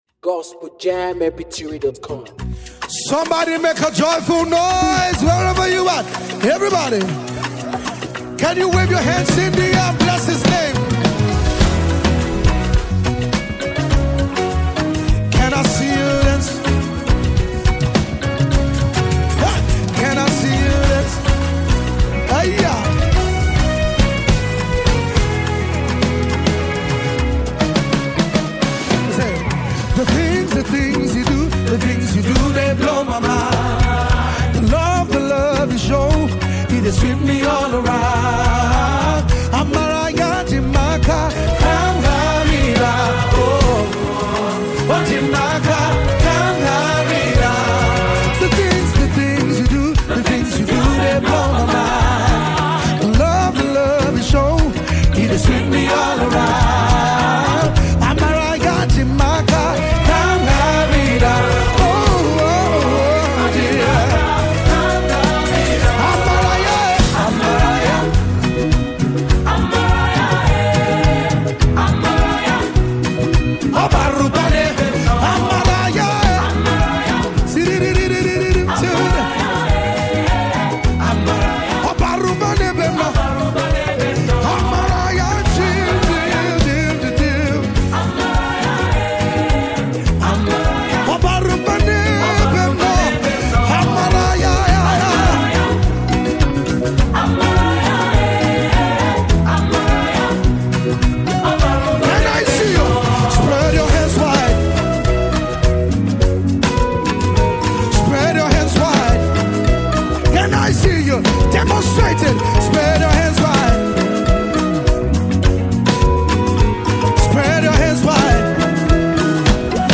With uplifting melodies and spirit-filled lyrics